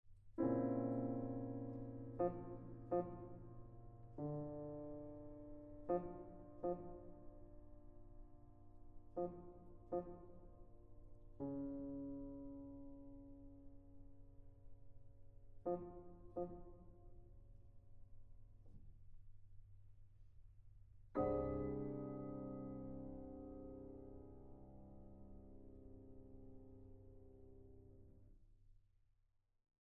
Piano
Recording: Mendelssohnsaal, Gewandhaus Leipzig